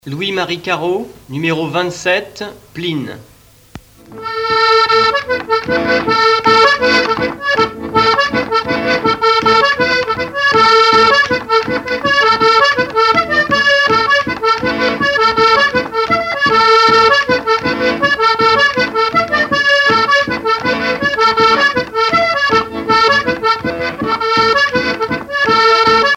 Plouray
danse : plinn
Pièce musicale éditée